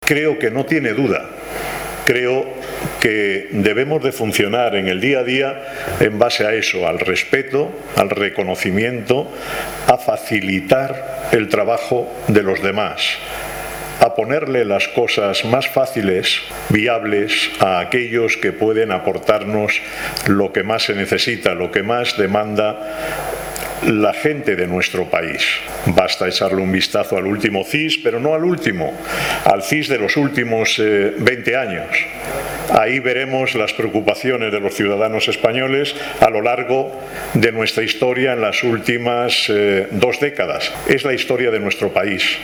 LA ONCE DEFIENDE EN EL FORO DE LA CEOE EL ESFUERZO COMPARTIDO FRENTE A LA CRISIS